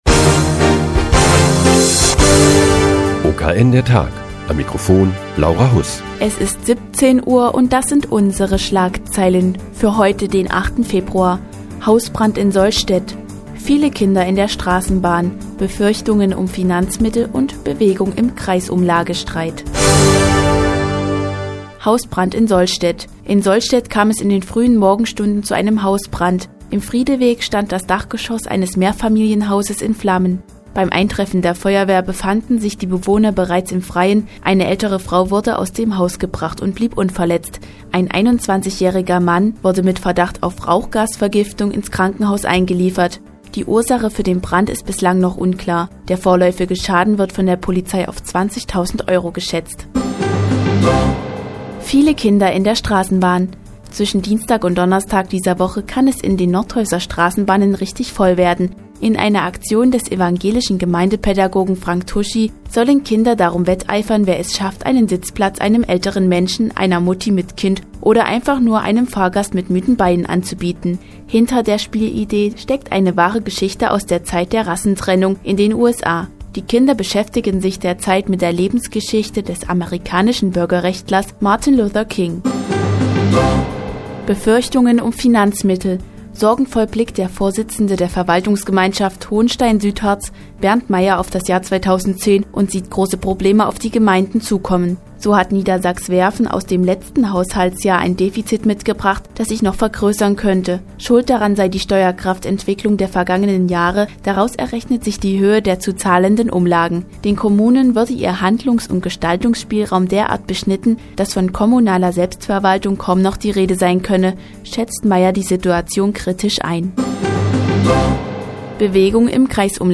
Die tägliche Nachrichtensendung des OKN ist nun auch in der nnz zu hören. Heute geht es um einen Hausbrand in Sollstedt und eine Aktion mit KIndern in den Nordhäuser Straßenbahnen.